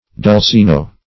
Search Result for " dulcino" : The Collaborative International Dictionary of English v.0.48: Dolcino \Dol*ci"no\, or Dulcino \Dul*ci"no\, n. [Cf. It. dolcigno sweetish.]